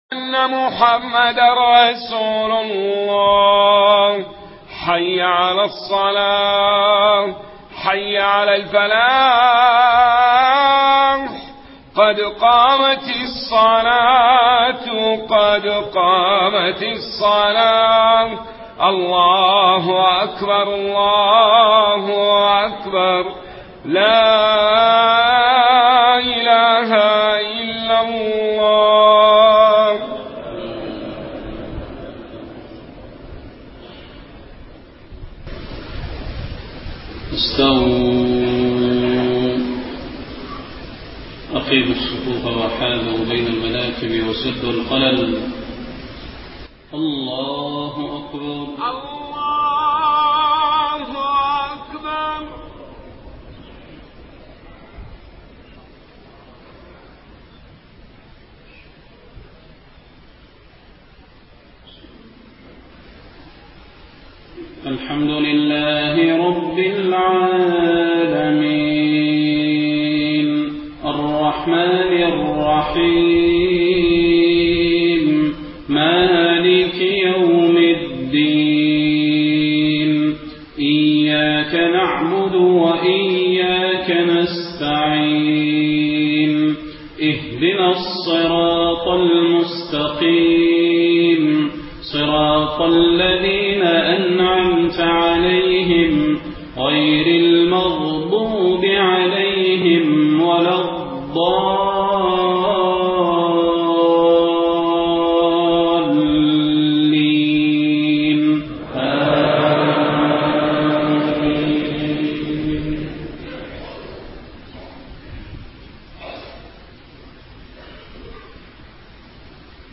صلاة العشاء 2 ربيع الأول 1431هـ سورة القيامة كاملة > 1431 🕌 > الفروض - تلاوات الحرمين